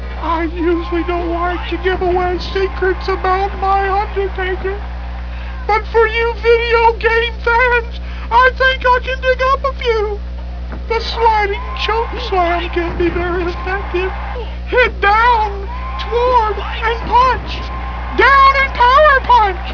Paul Bearer telling you what button combinations to hit to perform moves.